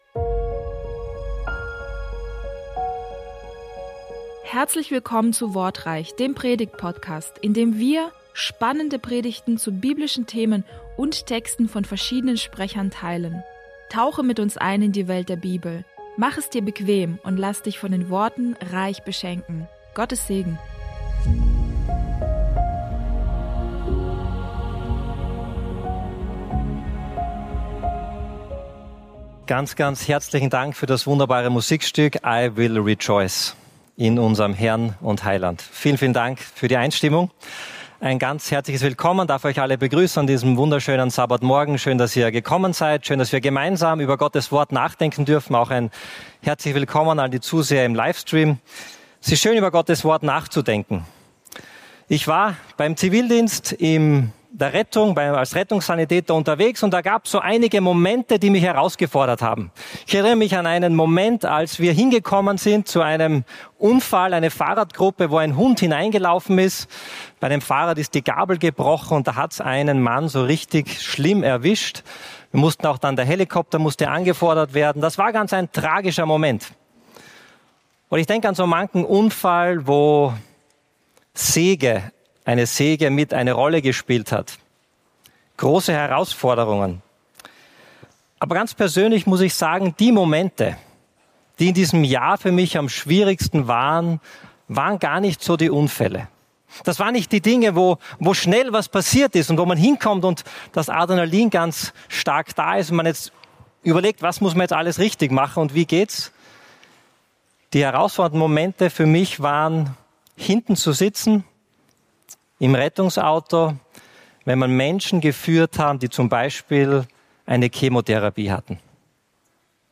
Hier hörst du Predigten aus Bogenhofen von unterschiedlichen Predigern, die dich näher zu Gott bringen und deinen Glauben festigen.